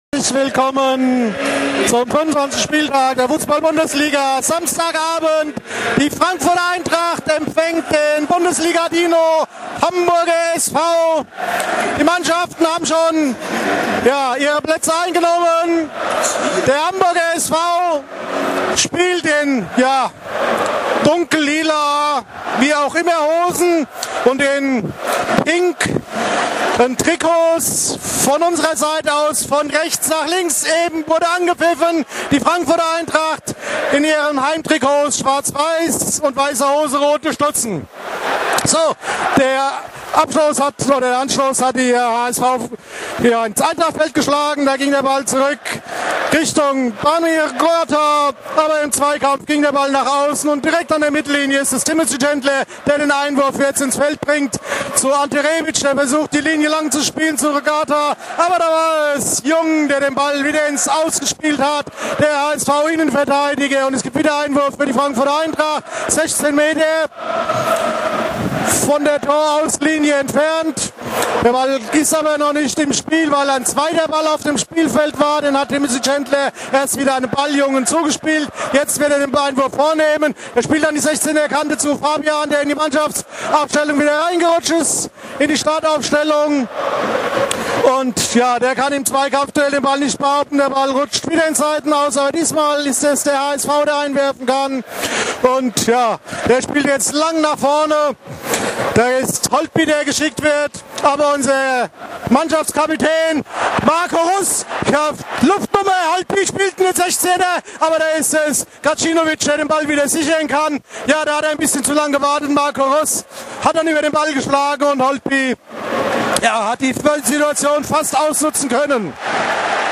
Die Spiel-Reportage im Player